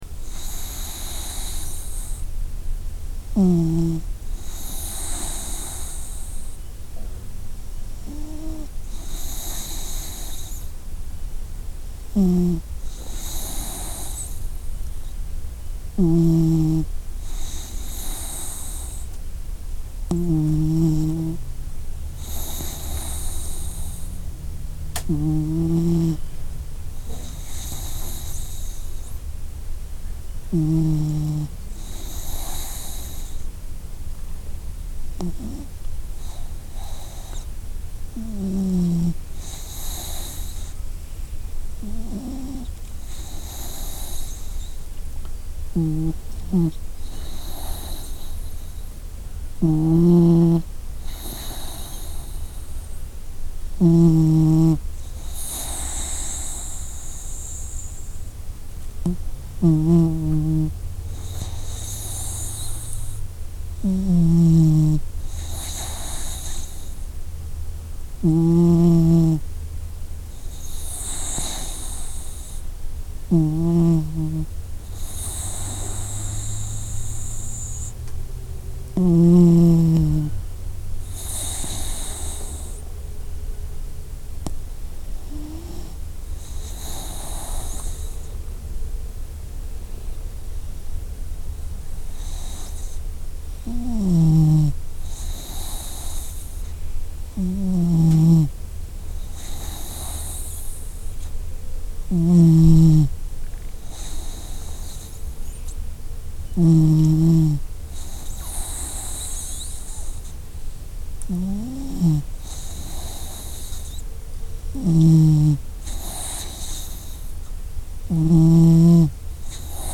LCom0uiXDhs_sleeping-dog-9202.mp3